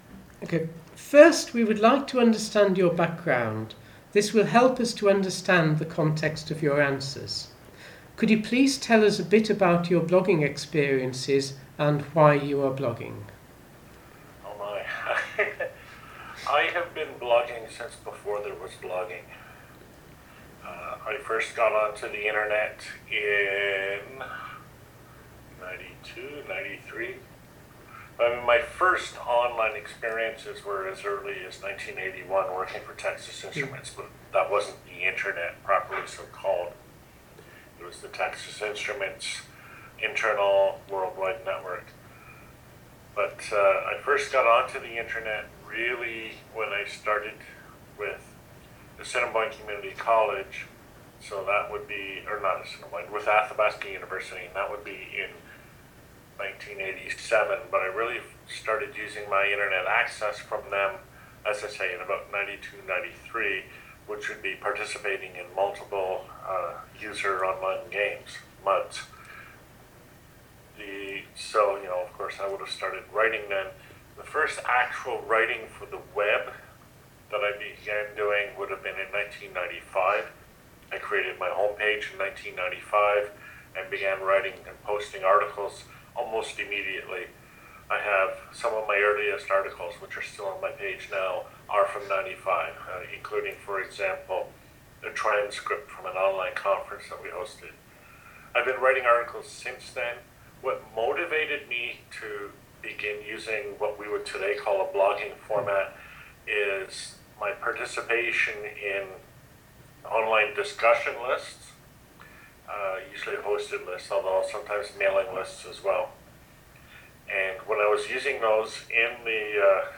Interview on Blogging
Interview about blogging. I talk a bit about my early days on the internet, and there's a history of my first articles on the web. I also talk about how to promote blogs so they will be found by other people.